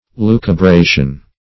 Lucubration \Lu`cu*bra"tion\, n. [l. lucubratio;cf. F.